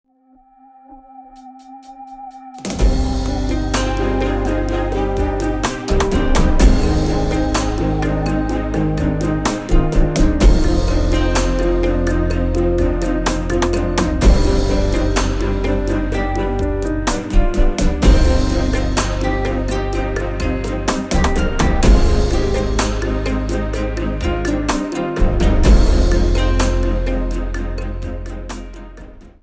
• Type : Instrumental / Audio Track
• Bpm : Adagio
• Genre : Oriental / R&B